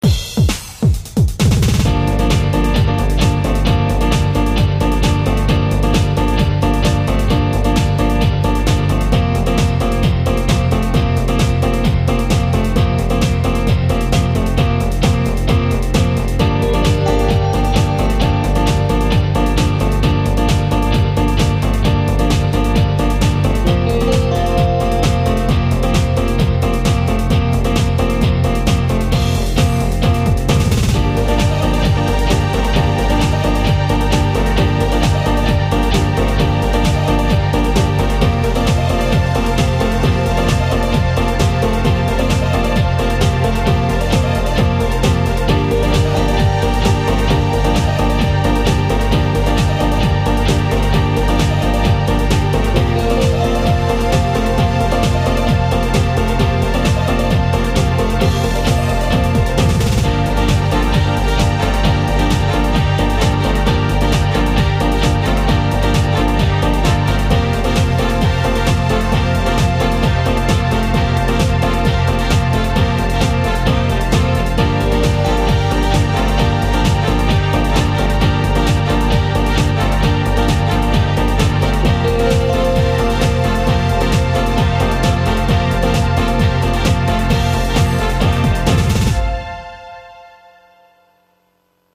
SD-50を使った音楽製作の練習。
078と080は、とりあえずフレーズループをしてるだけの、展開のない曲ばかりだ。
展開しているように見せかけるために、異なる楽器のフレーズを加え続けている。